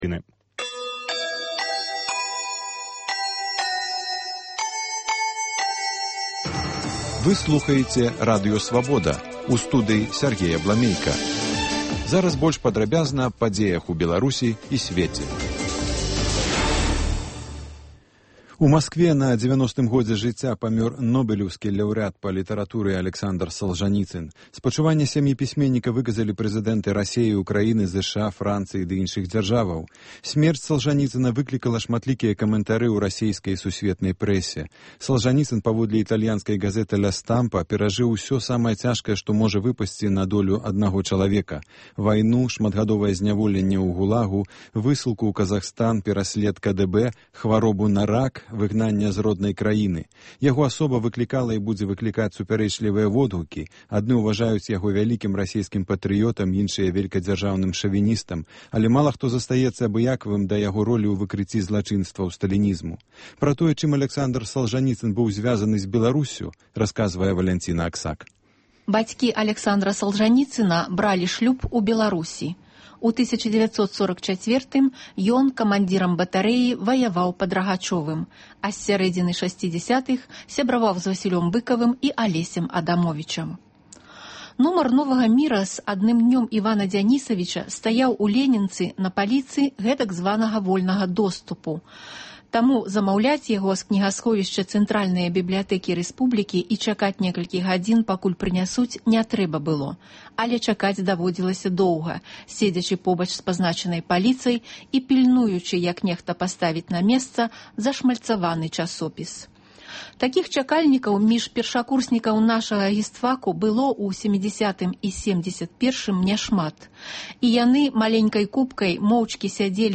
Паведамленьні нашых карэспандэнтаў, званкі слухачоў, апытаньні ў гарадах і мястэчках Беларусі.